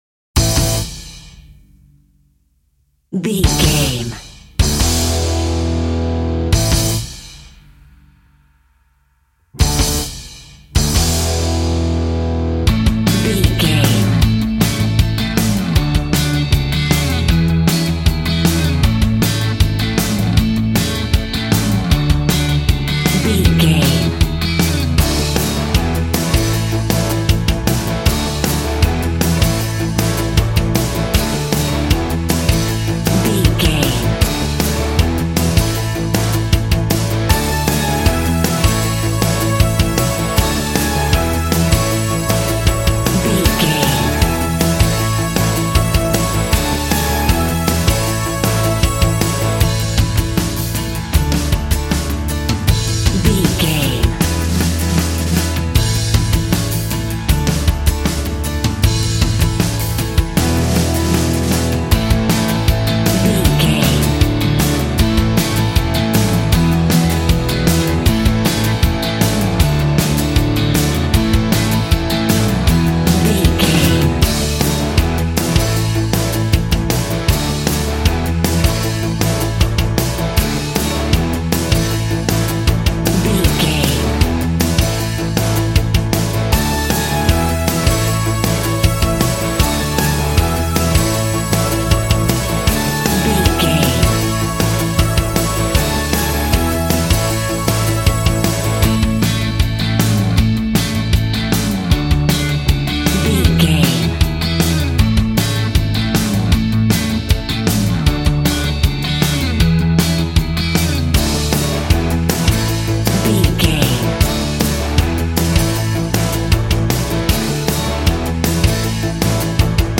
Ionian/Major
groovy
powerful
organ
bass guitar
electric guitar
piano